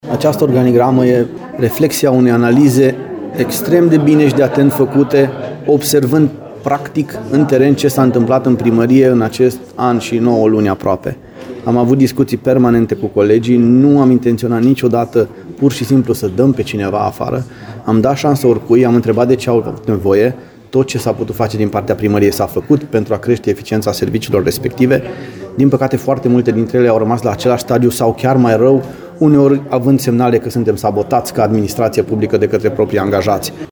Claudiu Buciu, primarul Lugojului: